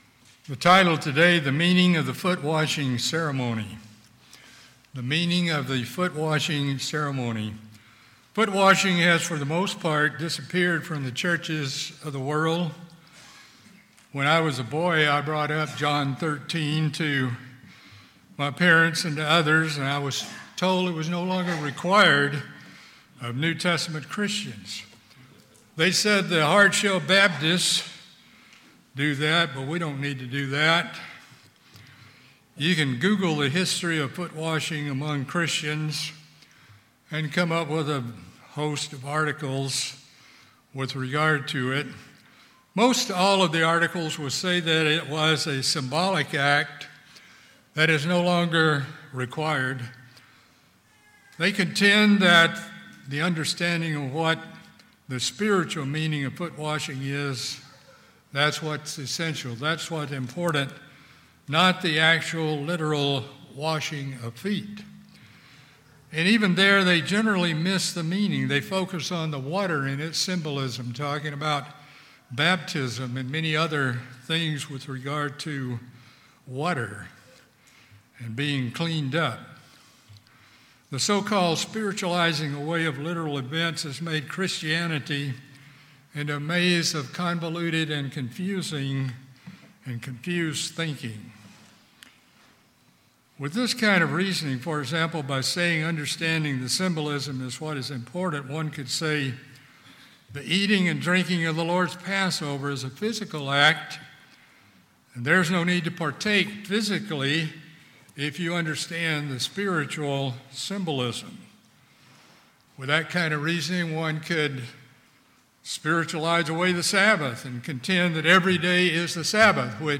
In this sermon we discuss the meaning and symbolism of the footwashing ceremony and the essentials for maintaining a consistent and constant footwashing attitude.